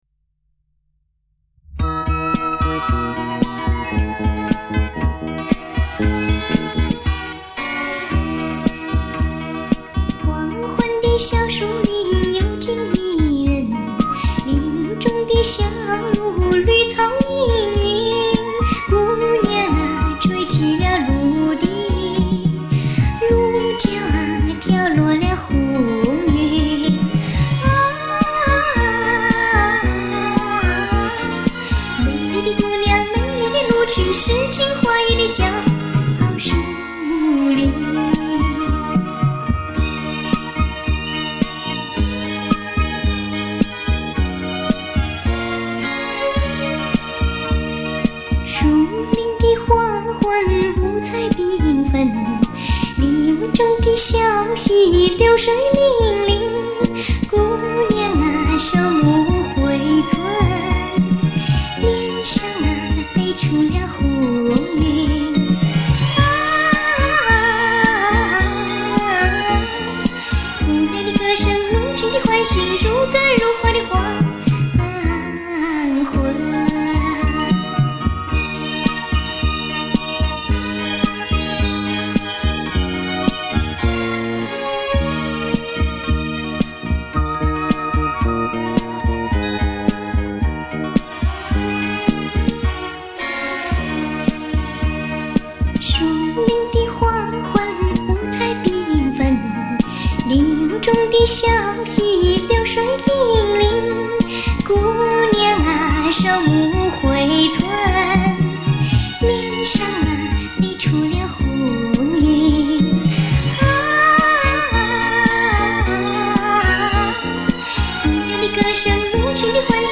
流派: 流行
介质: 卡带转录WAV/MP3.320K
小姑娘歌声甜美，当年风靡一时。